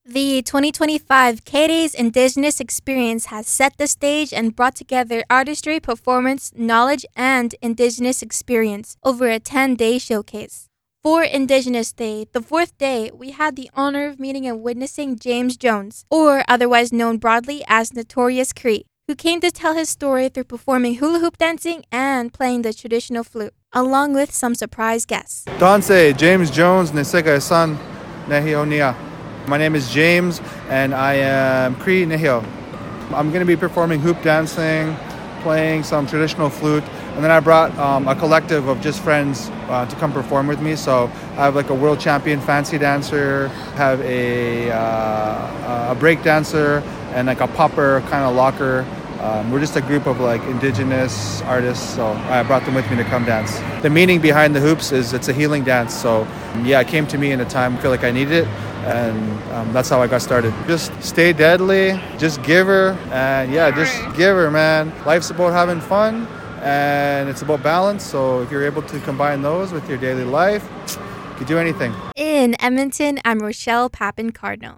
Here is a quick interview with Notorious Cree and his group who came to perform and represent at indigenous days for KDays…